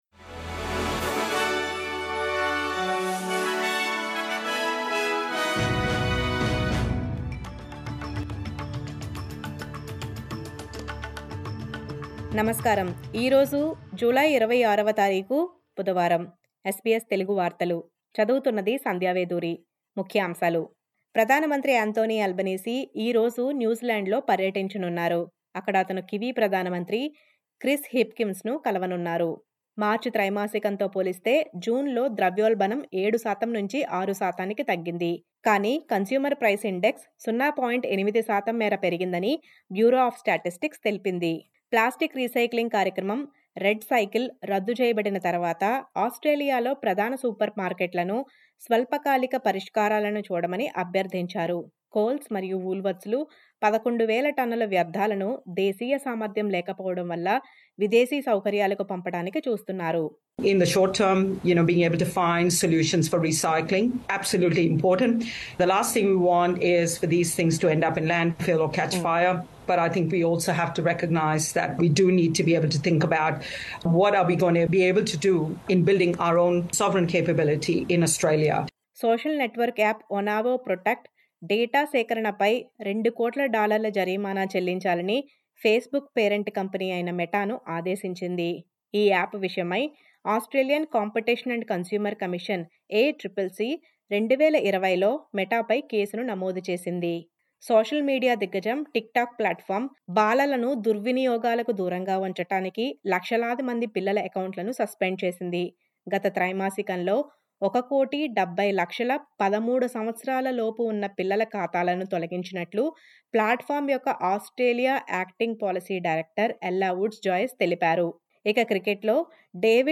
SBS తెలుగు 26/07/23 వార్తలు: వయోపరిమితి లేని బాలల అకౌంట్లు సస్పెండ్ చేస్తున్న TikTok